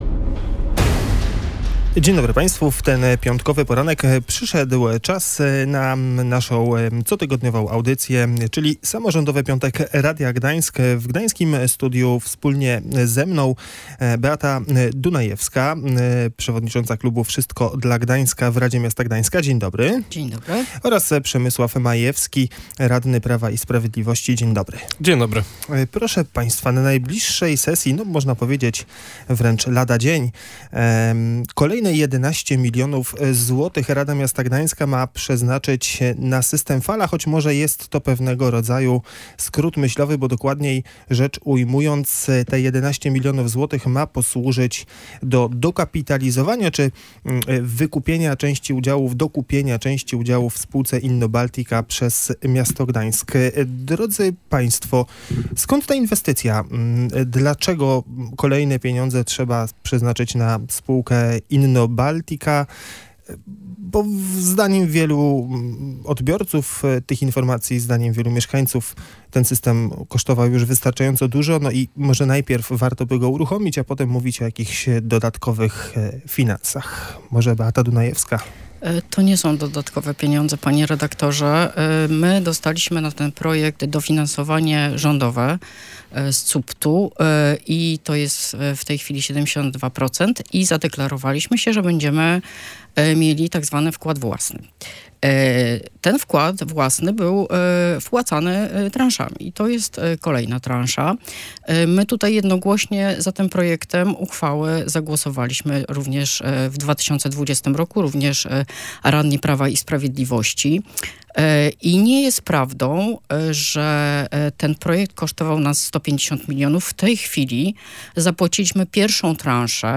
Dyskusja radnych